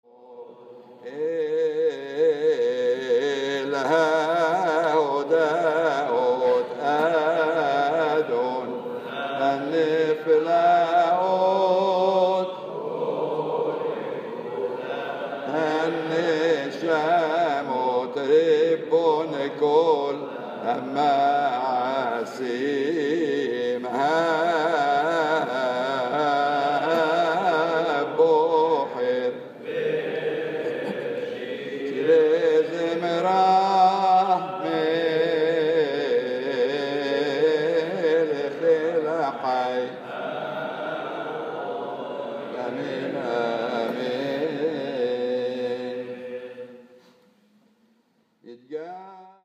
Maqam Ajam